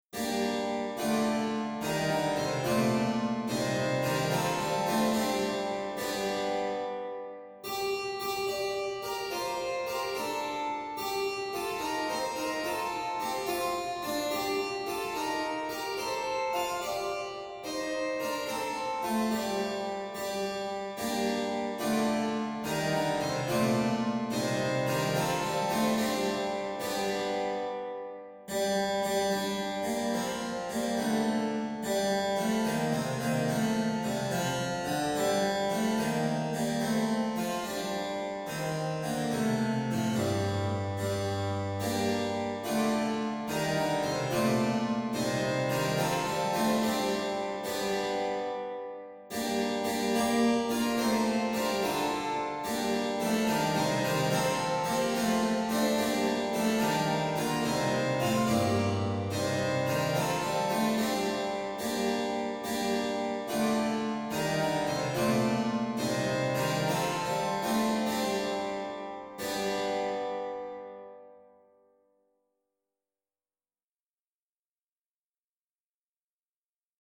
Advent carol